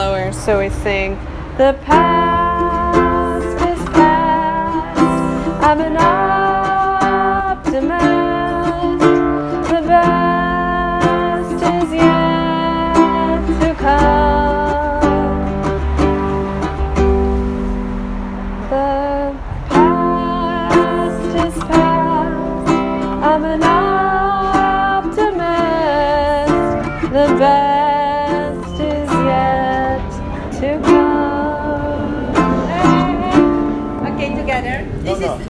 This is a recording of the chorus of the song: